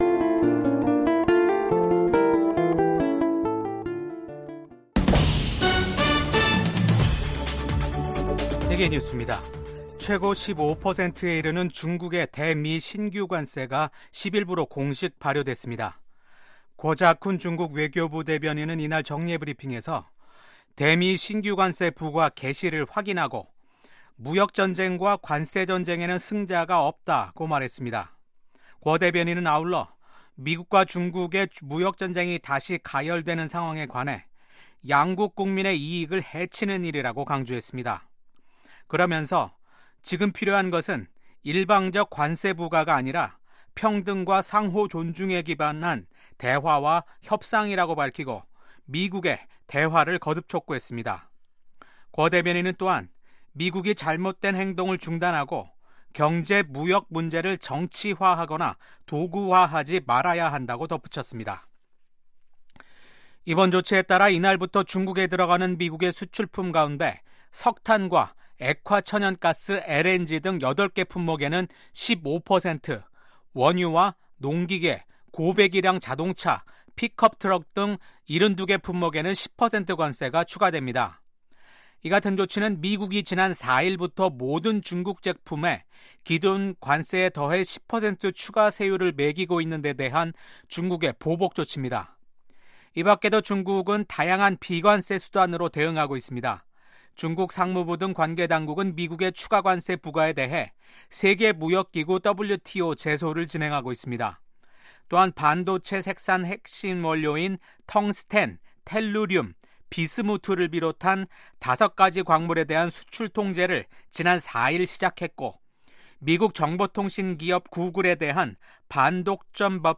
VOA 한국어 아침 뉴스 프로그램 '워싱턴 뉴스 광장'입니다. 도널드 트럼프 미국 대통령이 한반도 안정을 위한 노력을 계속하고 김정은 위원장과도 관계를 맺을 것이라고 밝혔습니다. 미국 정부 고위 당국자가 북한의 완전한 비핵화가 트럼프 행정부의 변함없는 목표라는 점을 재확인했습니다. 김정은 북한 국무위원장은 미국이 세계 각지 분쟁의 배후라고 주장하면서 핵 무력 강화 방침을 재확인했습니다.